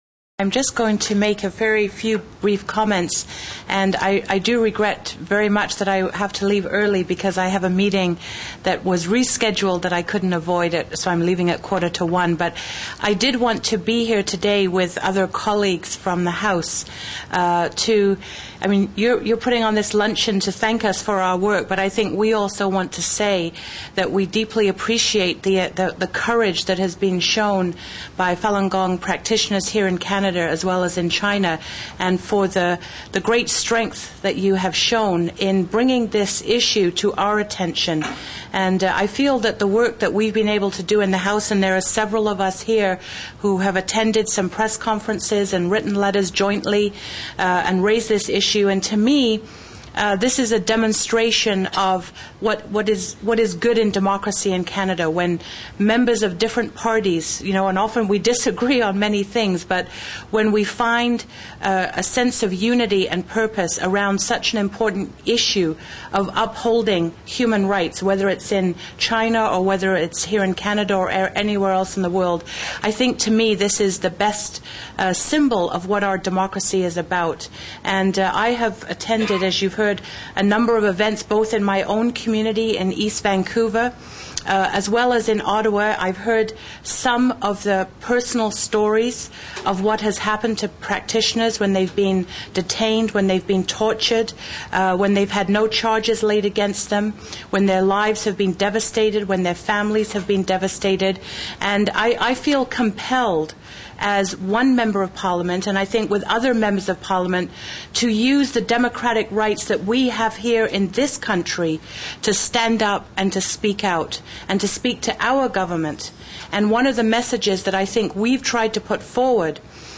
VIP_speeches_Ottawa_mp3.wav